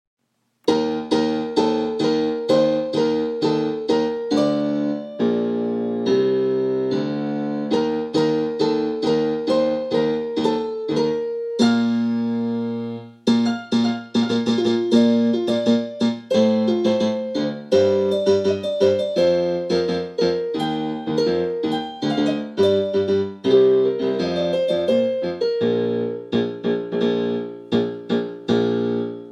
千葉ロッテマリーンズ #7 鈴木大地 応援歌